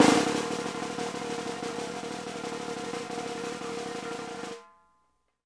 PRESSROLL -R.wav